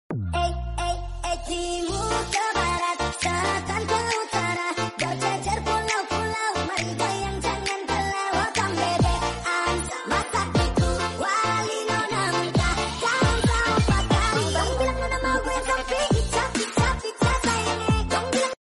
kucing meong meong joget lucuuuu